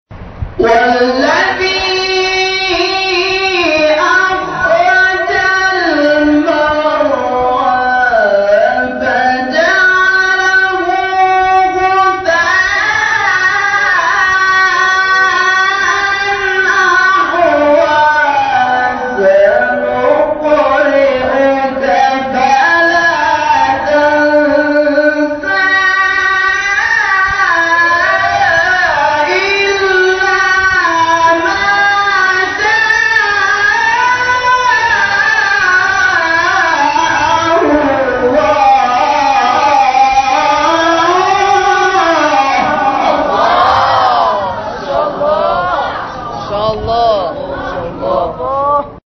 این فرازها به ترتیب در مقام‌های؛ نهاوند، عزام، بیات، حجاز و رست اجرا شده است.